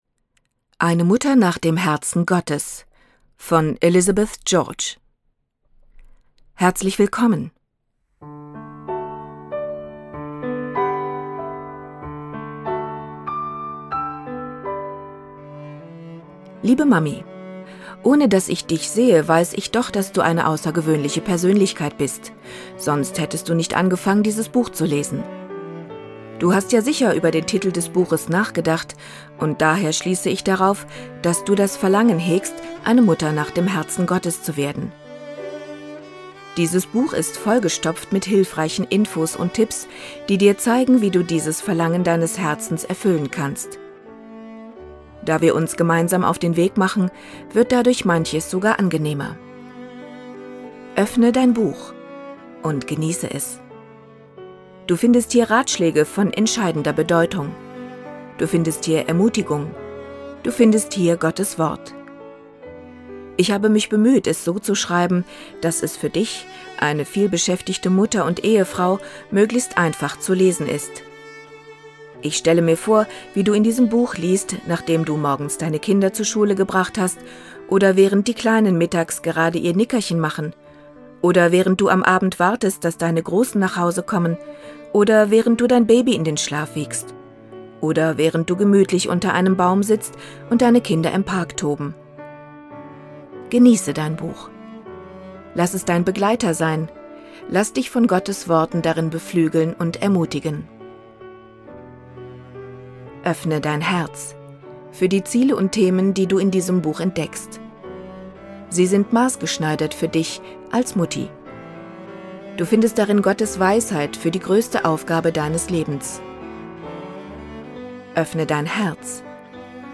Inhalt Hörbuch